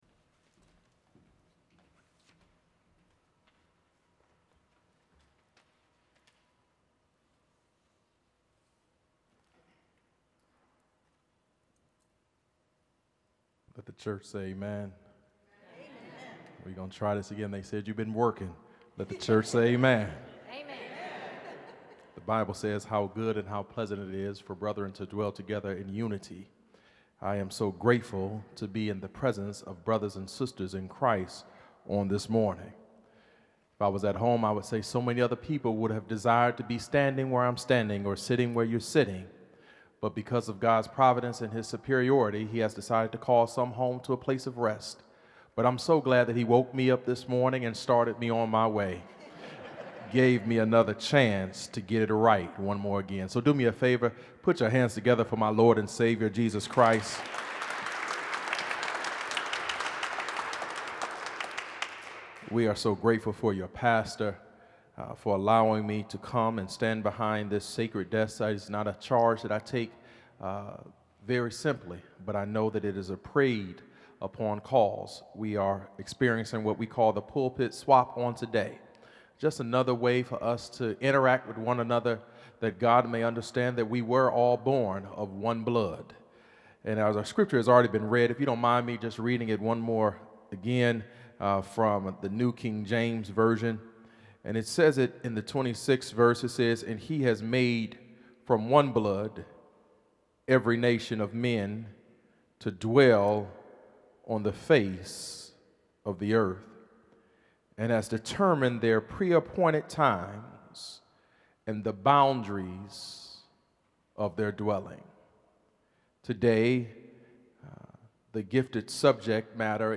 2020 Sermons - First Presbyterian Battle Creek